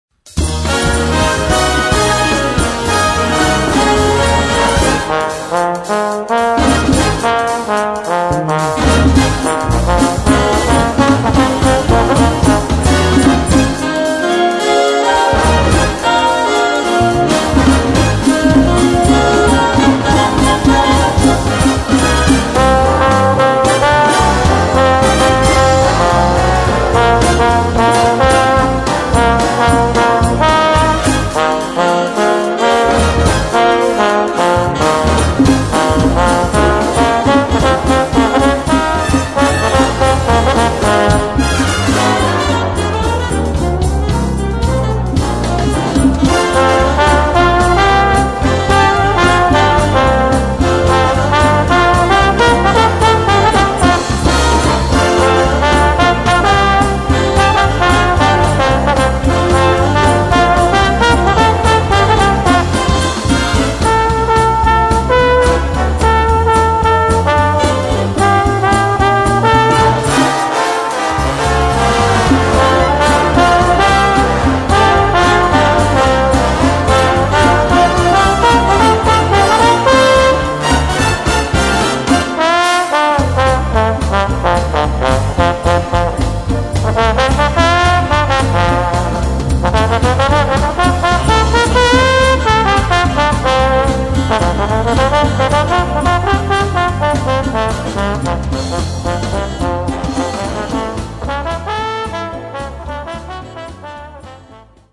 Voicing: Viola and Brass Band